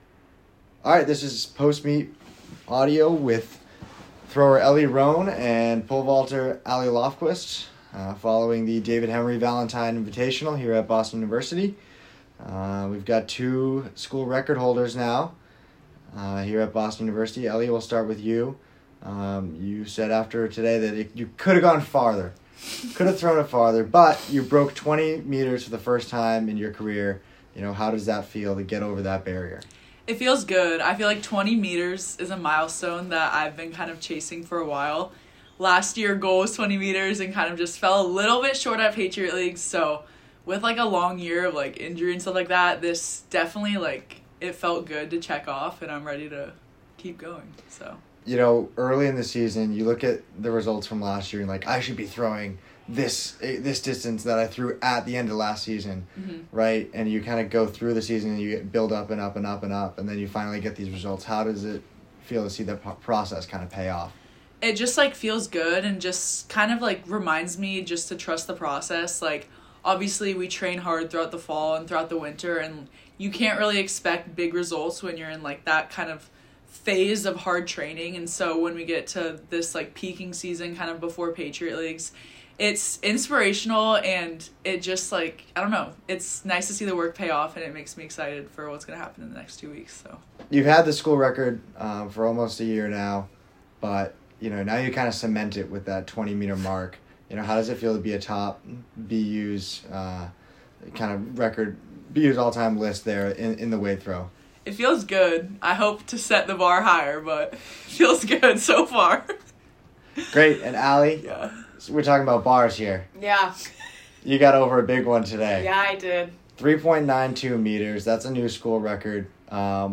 Women's Track & Field / Valentine Invitational Postmeet Interview